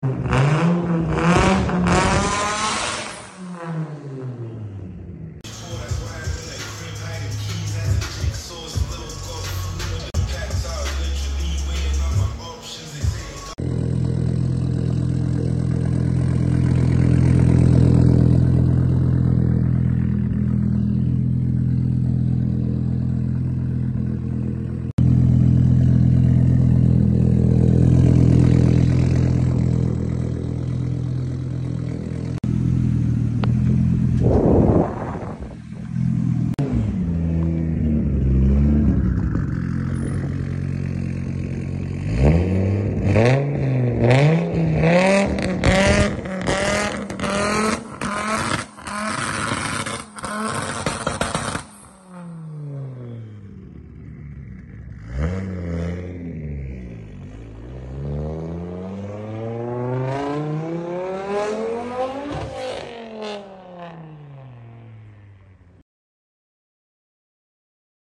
AUDI S2 SOUNDING CRAZYY NOW Sound Effects Free Download
🔥🔥 WE PREVIOUSLY BUILT A FULL SYSTEM FOR THIS. CAME BACK IN FOR INTERCHANGEABLE BACKBOX DELETE.